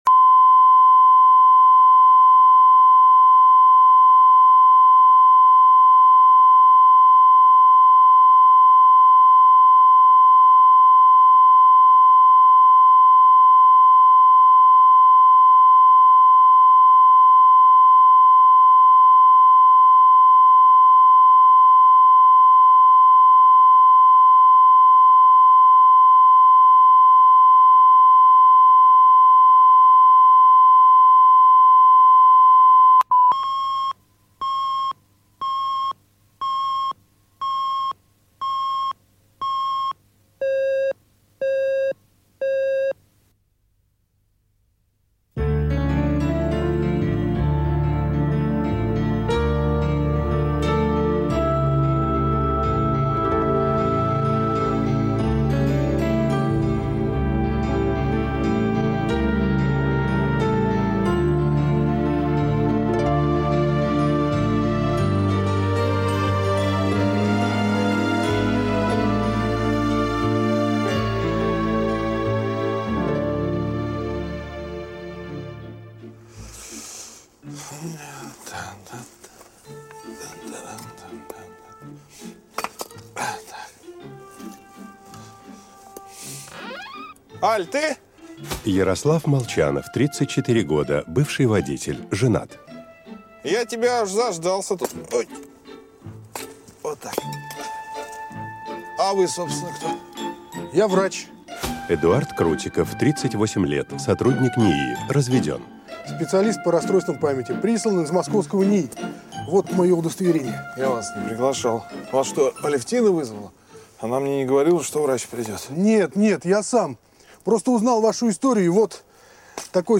Аудиокнига С чистого листа | Библиотека аудиокниг